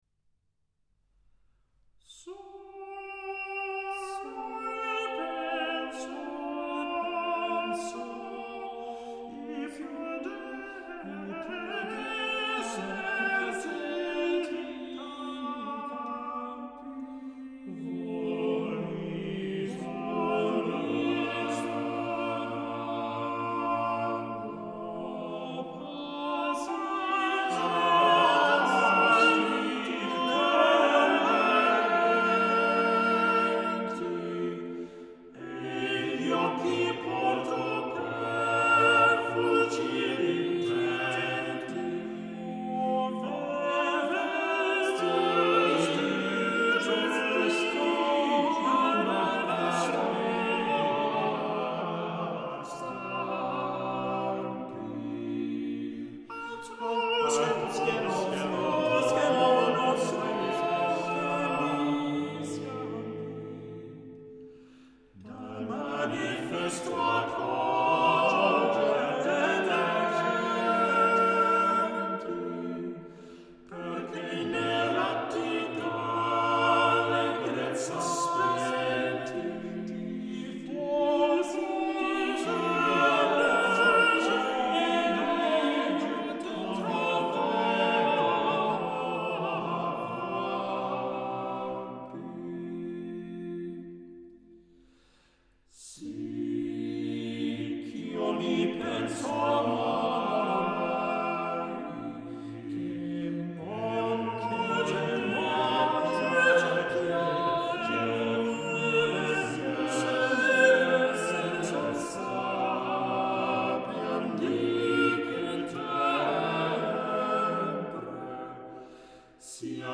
One of the world’s finest vocal chamber groups
at the St Gerold monastery in Austria